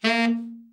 TENOR SN  15.wav